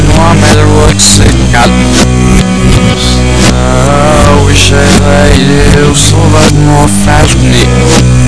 Backwards